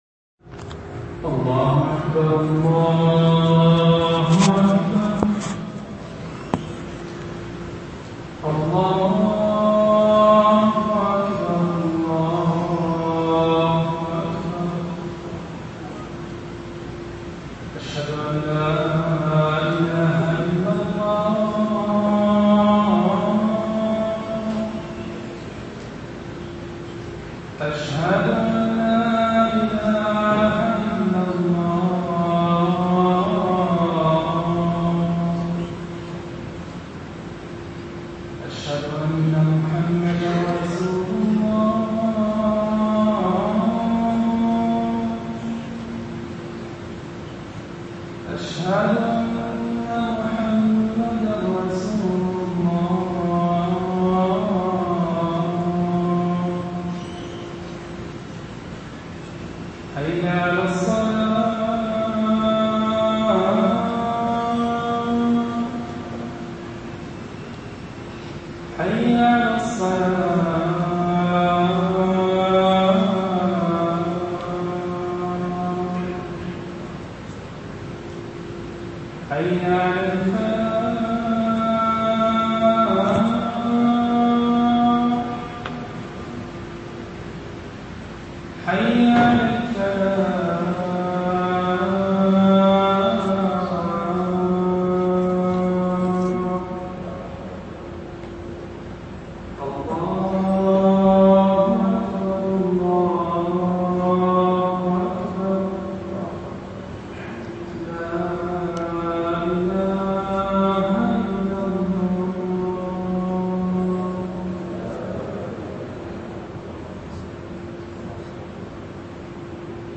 نعمة الأمن في الأوطان خطبة